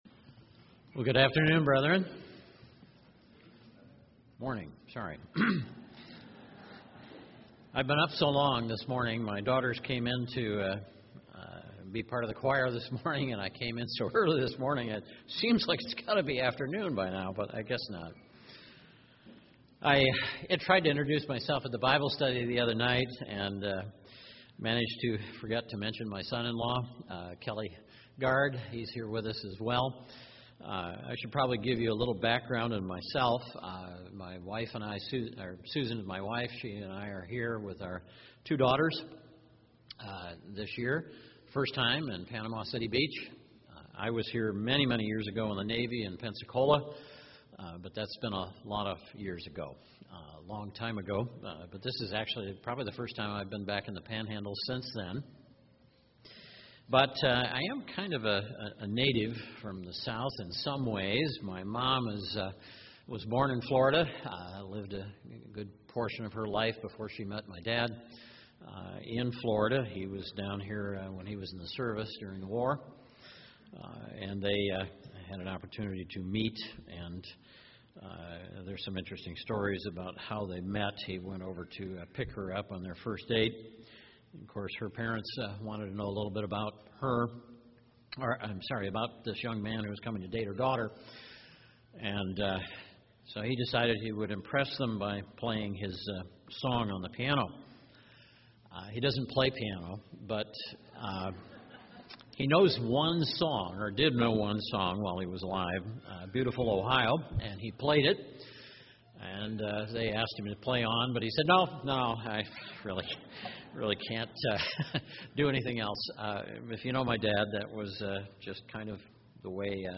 This sermon was given at the Panama City Beach, Florida 2011 Feast site.